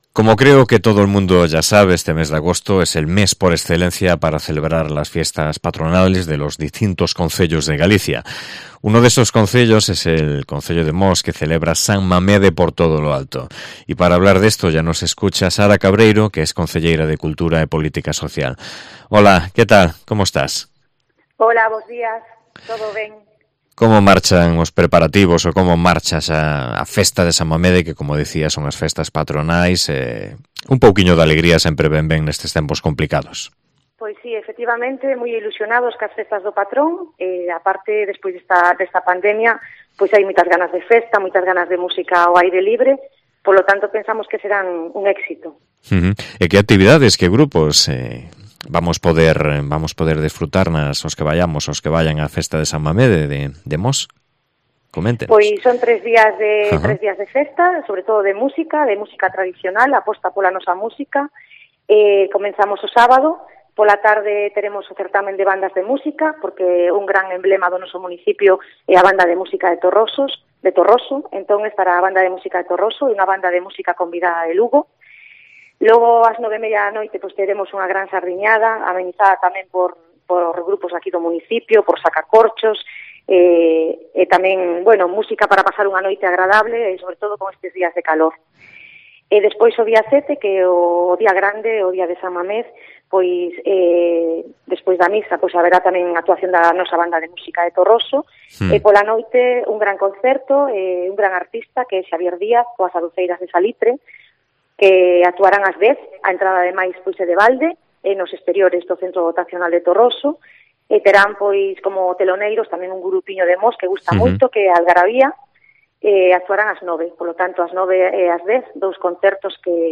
AUDIO: Sobre ellas hablamos con Sara Cebreiro, concelleira de Cultura e Política Social